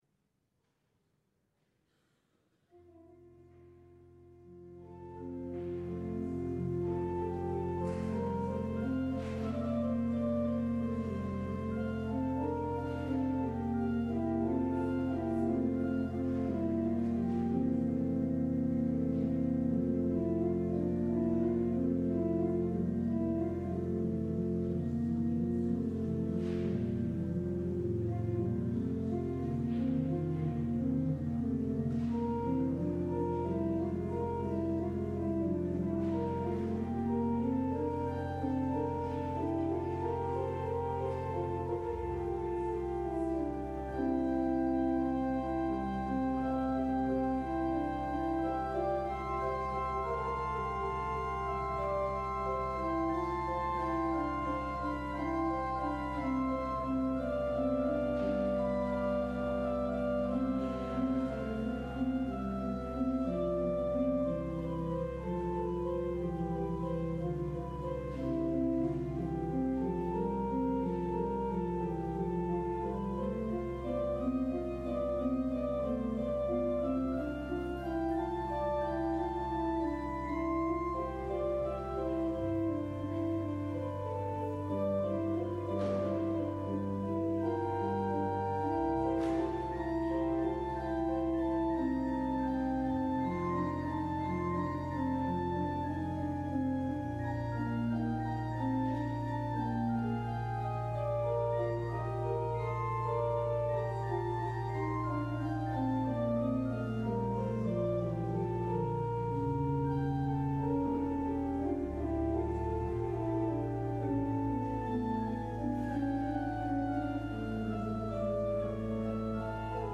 LIVE Morning Worship Service - The Prophets and the Kings: Elijah and the Widow